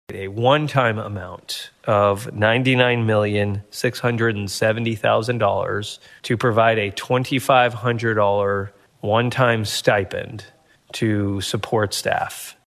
CLICK HERE to listen to commentary from State Senator Adam Pugh.